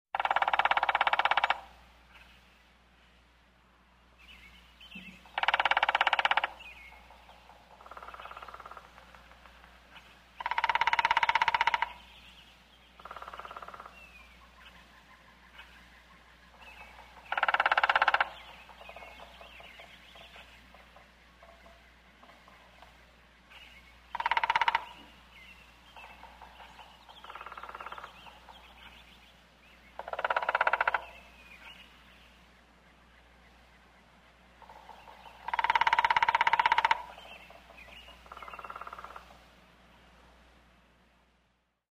Стук дятла